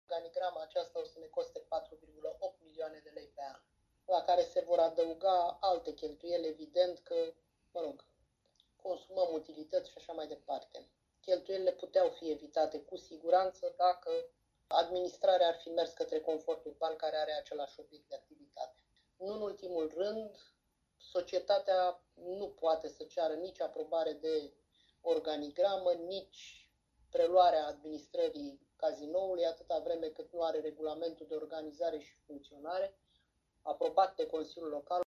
Organigrama societății Patrimoniu Constanța Litoral, cea care va administra și exploata Cazinoul, a stârnit cele mai multe discuții în ședința de miercuri a Consiliului Local Constanța.
Consilierul independent Felicia Ovanesian a anunțat că votează împotriva acestui proiect și a explicat motivele.